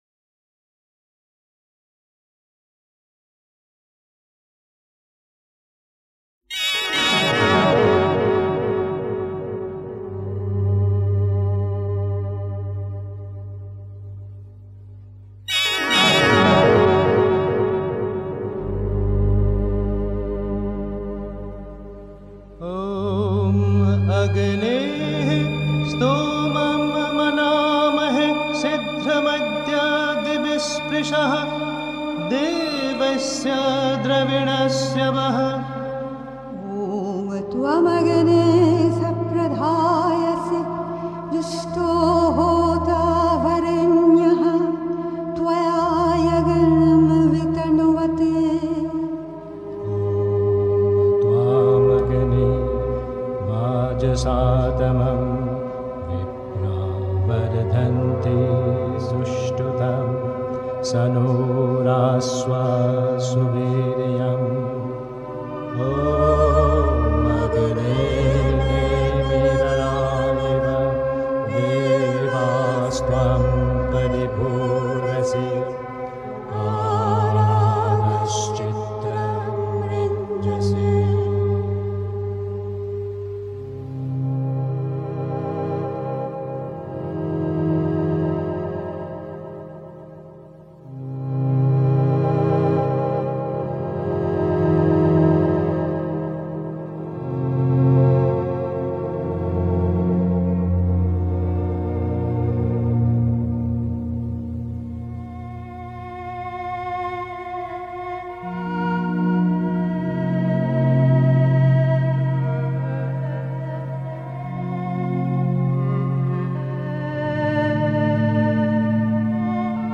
2. Aufrichtigkeit (Die Mutter, The Sunlit Path) 3. Zwölf Minuten Stille.